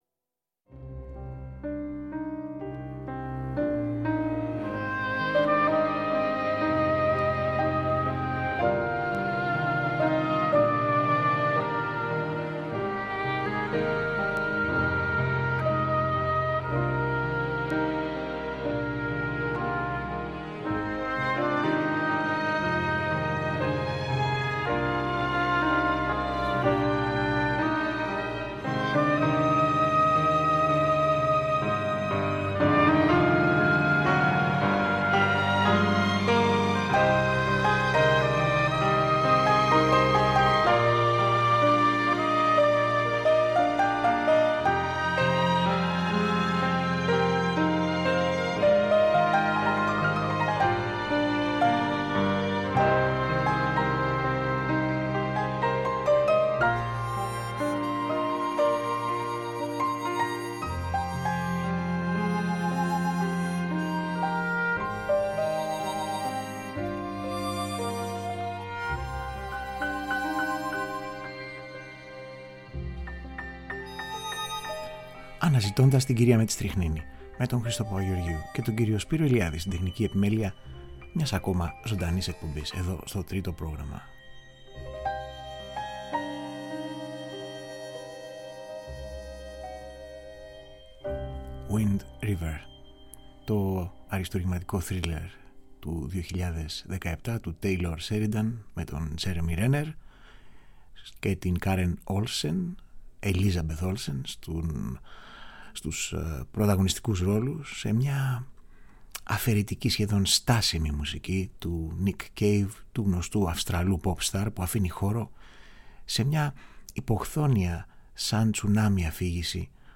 κινηματογραφικη μουσικη